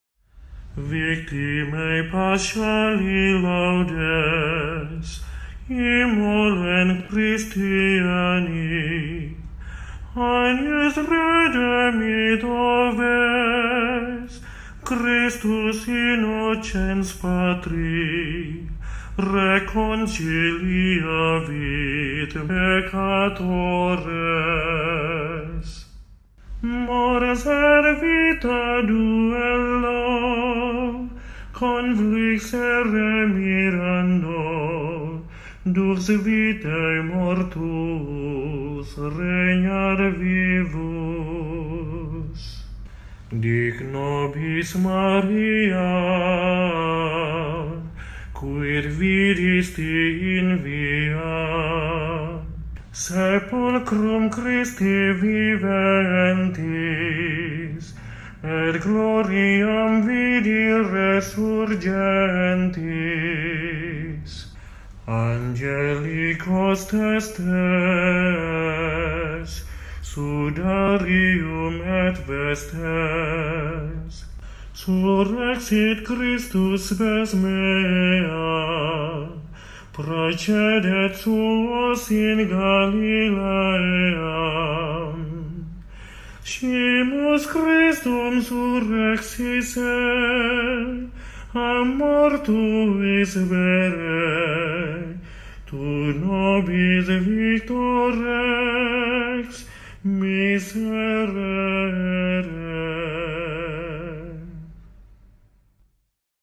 SEKUENSIA (wajib dinyanyikan pada Hari Minggu Paskah I, sebelum Bait Pengantar Injil)